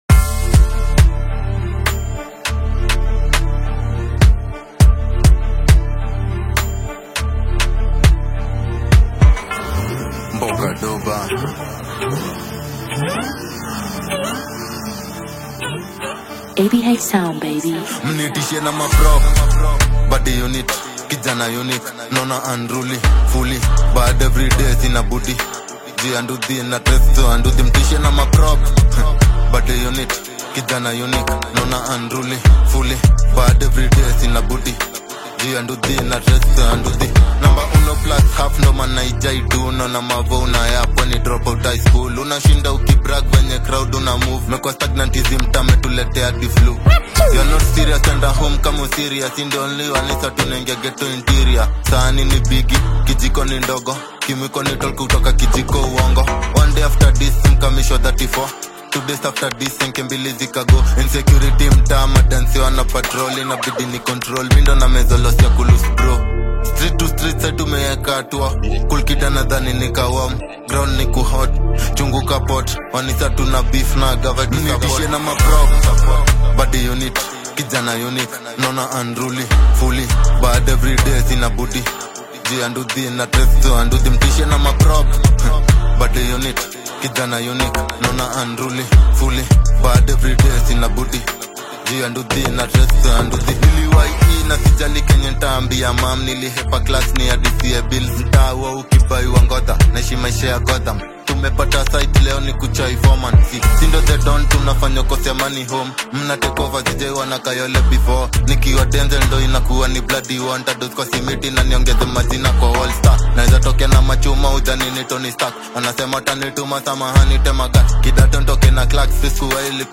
gritty hip‑hop/trap single